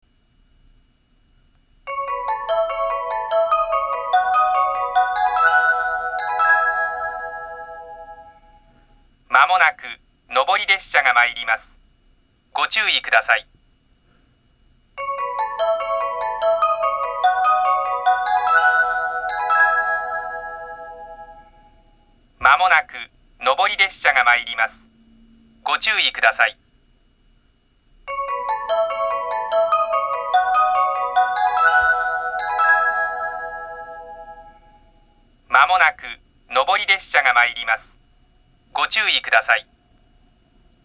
20191月中旬頃に放送装置が更新され、メロディーで始まるタイプの接近放送になりました。
接近放送
男性による接近放送です。
3回鳴動します。